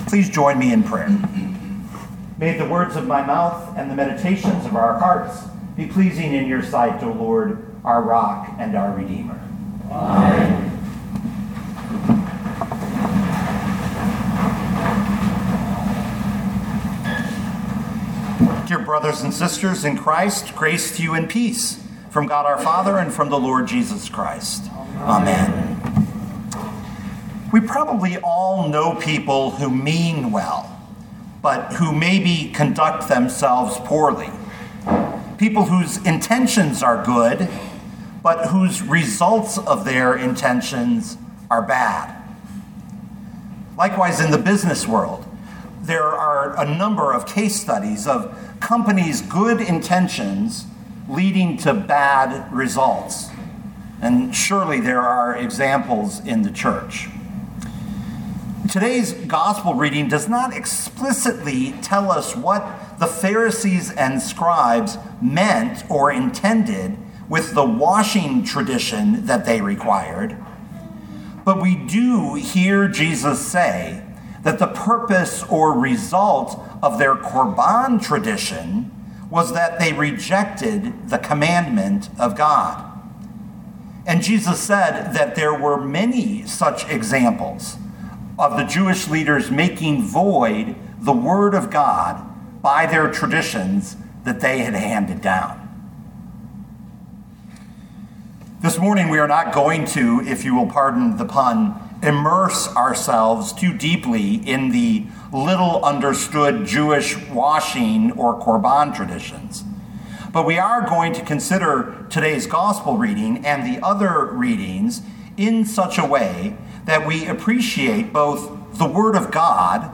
2024 Mark 7:1-13 Listen to the sermon with the player below, or, download the audio.